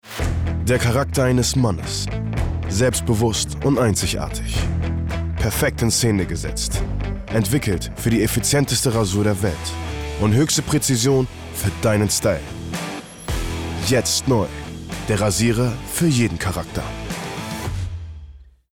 markant, dunkel, sonor, souverän, plakativ
Mittel minus (25-45)